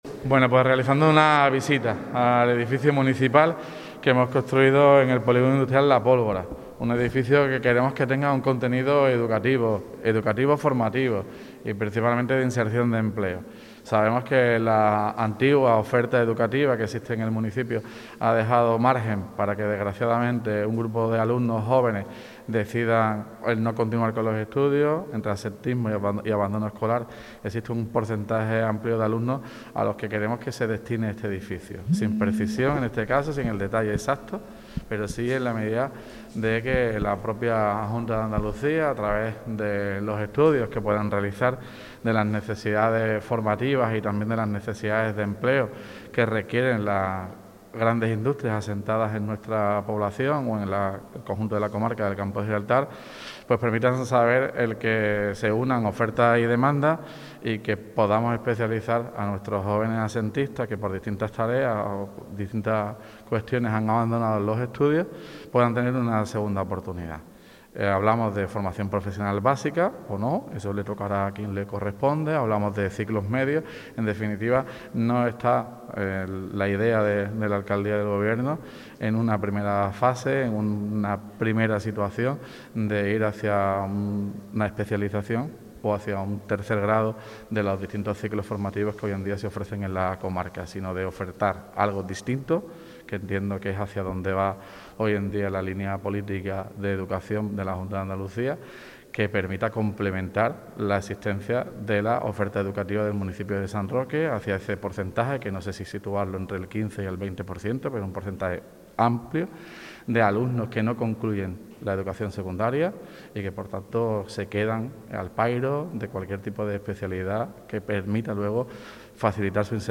EDIFICIO_LA_POLVORA_TOTAL_ALCALDE.mp3